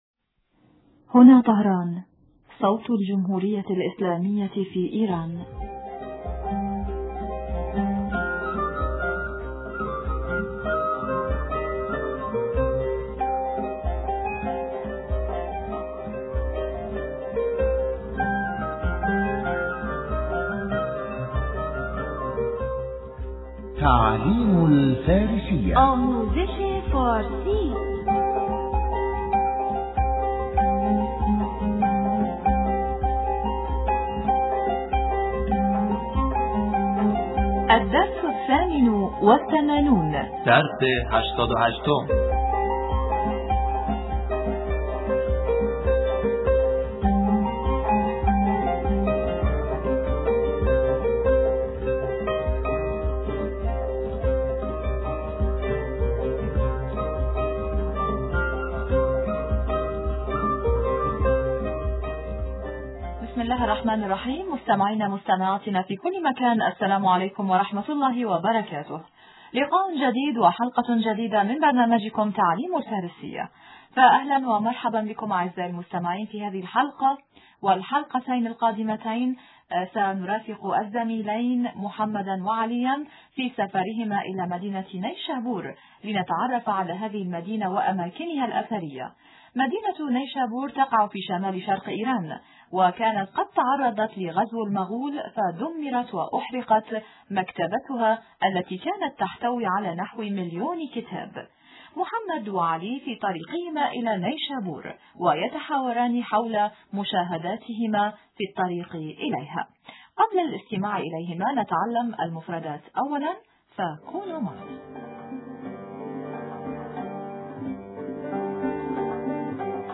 تعلّم الفارسية - الدرس ۸۸ - حوار حول مدينة نيشابور